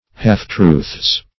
(h[aum]f"tr[=oo][th]z)